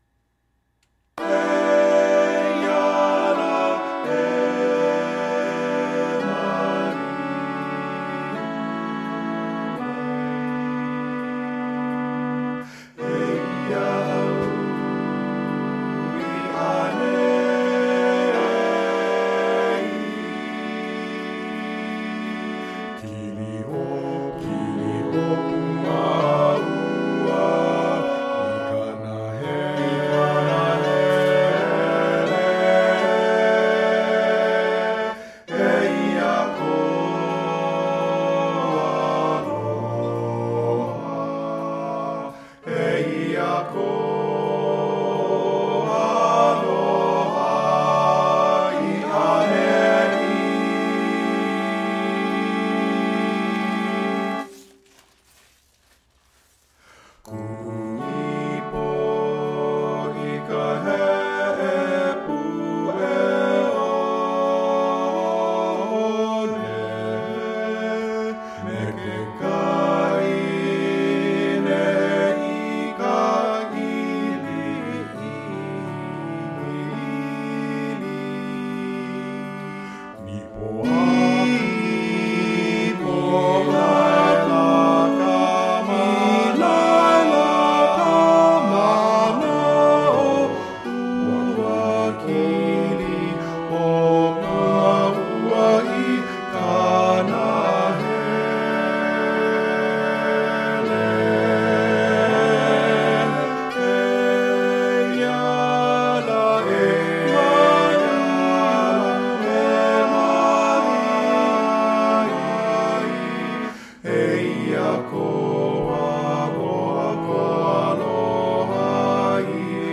ALL   Instrumental | Downloadable